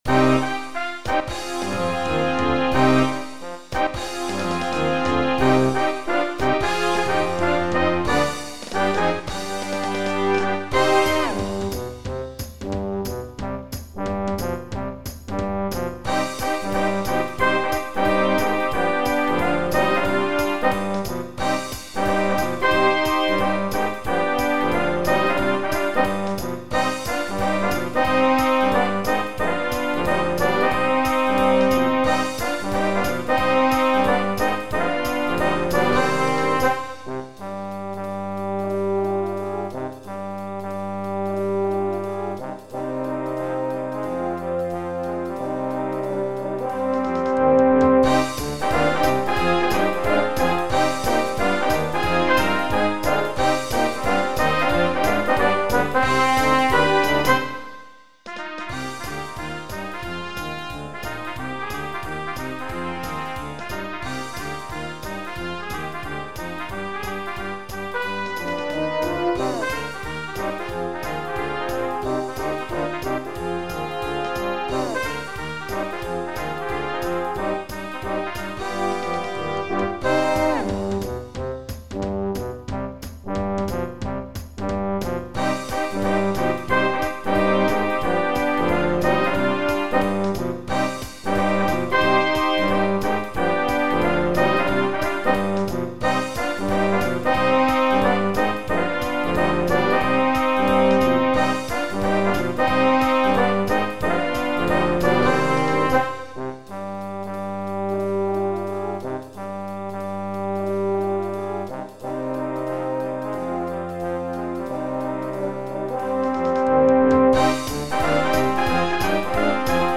Voicing: 11 Brass w/ Percussion